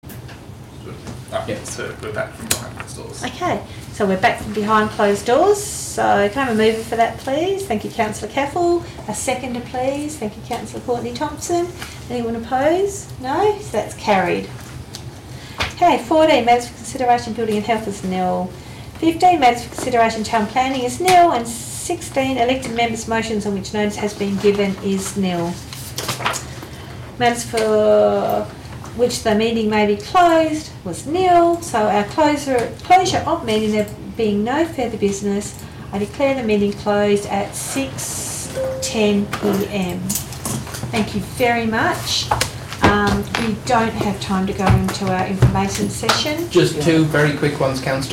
Location: Tammin Council Chambers